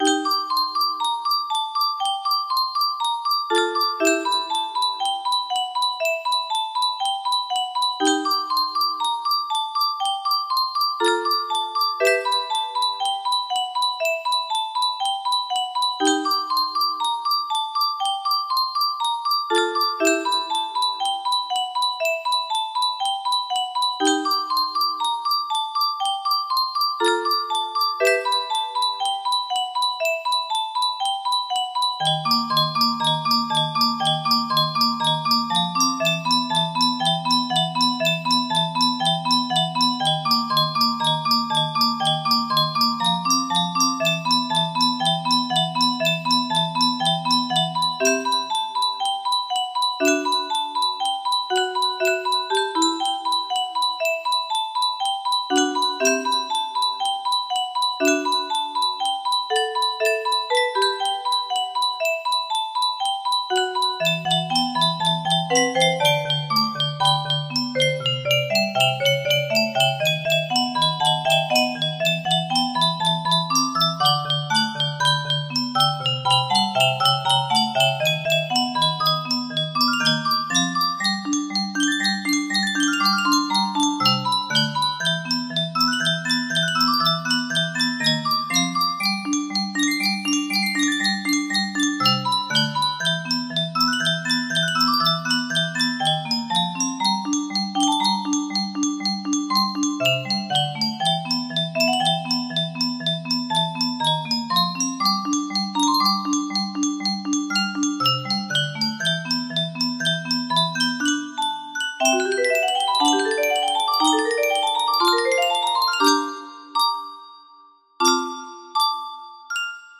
Full range 60
The mood is more serious than usual, too.